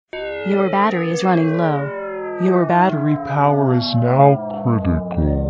Звук батареи отключения